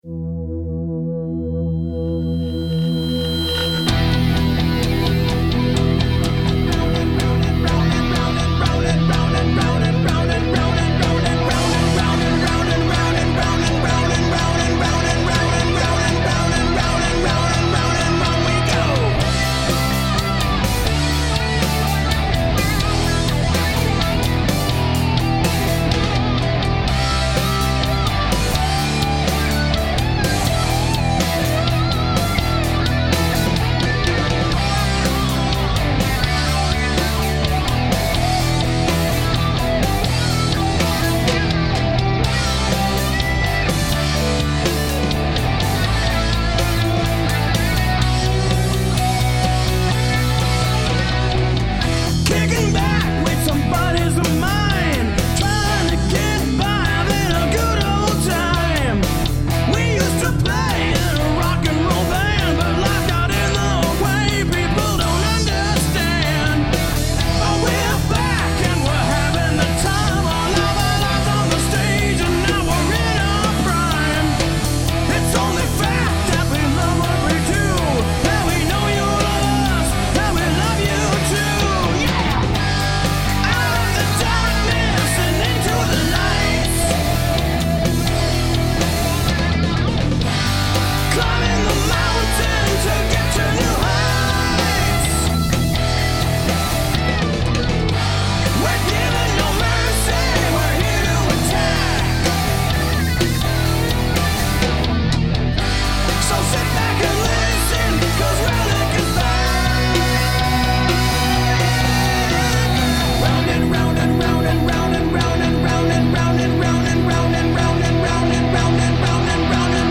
Ok, so I already know that my weakest point in the song is probably going to be the cymbals, because I only have high hats and one crappy crash that is actually an older high hat. I want to record our band to make a new CD and this is kind of the sound I'm heading towards.